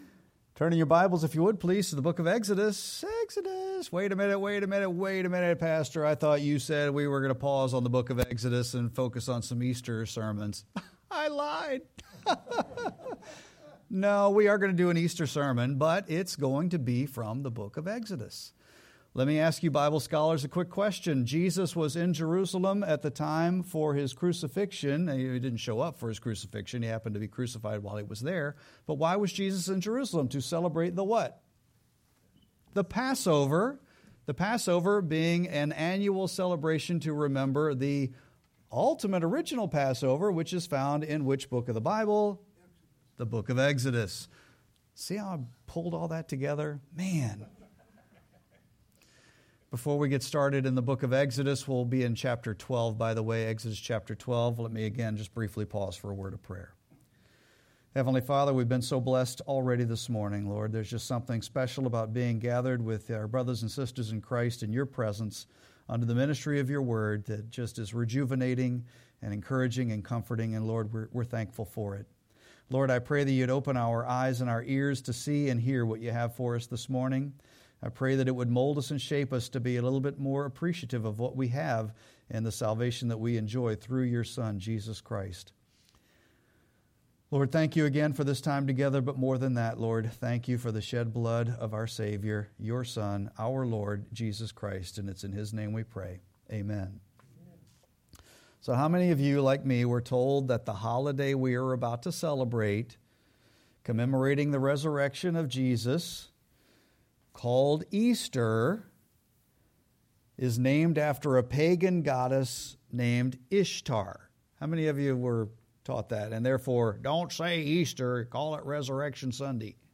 Sermon-3-22-26.mp3